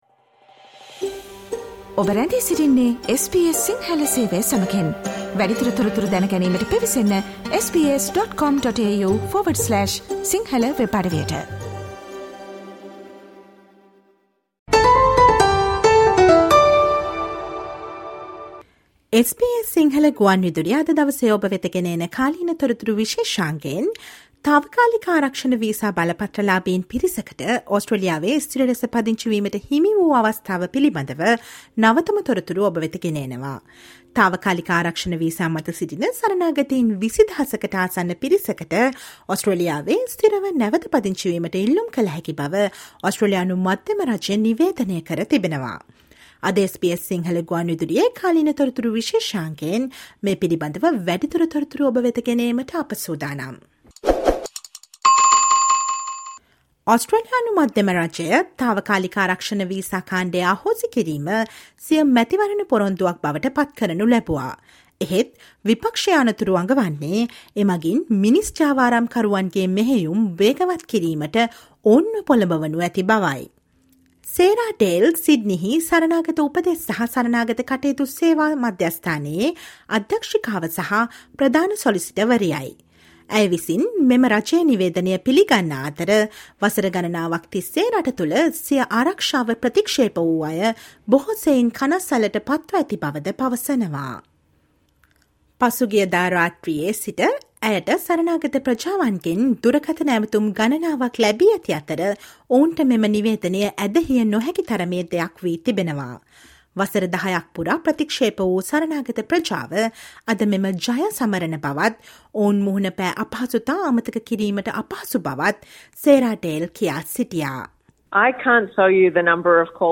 වසර 10 ක් පුරා බලා සිටි තාවකාලික ආරක්ෂණ වීසා බලපත්‍රලාභීන්ට ඔස්ට්‍රේලියාවේ ස්ථිර ලෙස පදිංචි වීමට අවස්ථාව හිමි වීම පිළිබඳ නවතම තොරතුරු දැන ගන්න සවන් දෙන්න අද SBS සිංහල ගුවන් විදුලි වැඩසටහනේ කාලීන තොරතුරු විශේෂාංගයට.